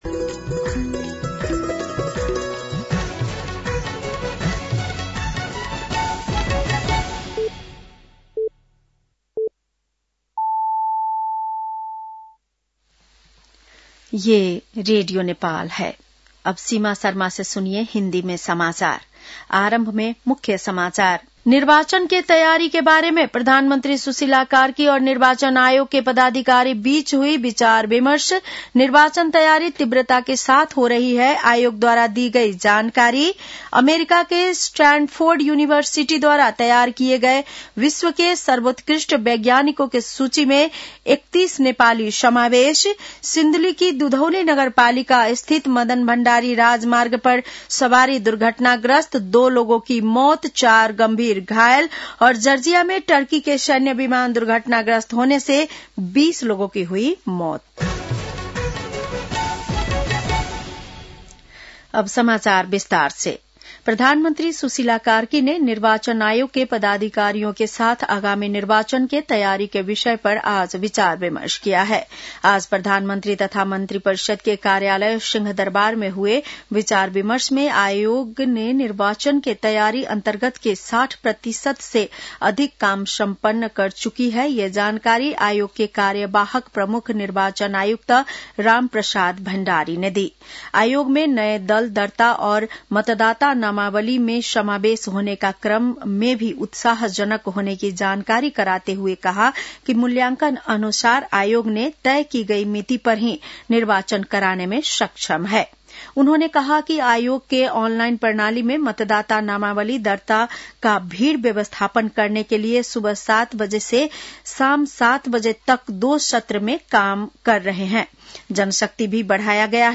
बेलुकी १० बजेको हिन्दी समाचार : २६ कार्तिक , २०८२
10-pm-hindi-news-7-26.mp3